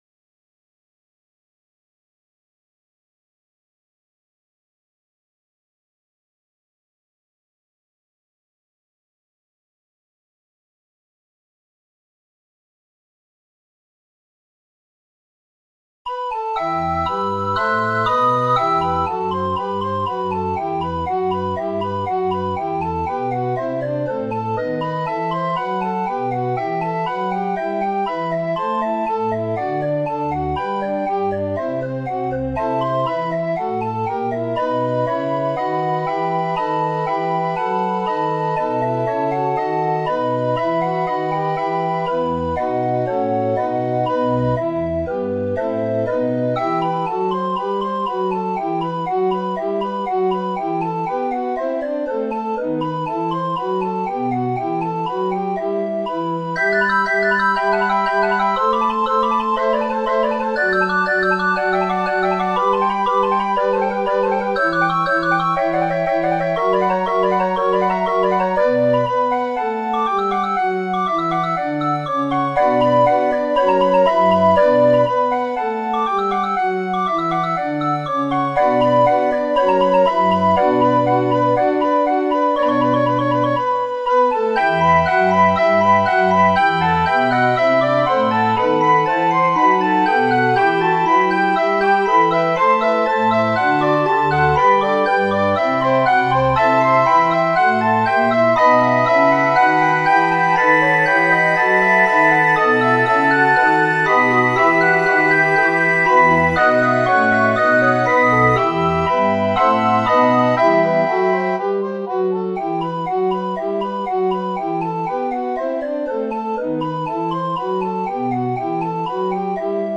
A fantastic and authentic Baroque sound
Here you have the continuo part from a sonata by Vivaldi
Warning! The mp3 music starts after about 15 seconds so care the volym!!!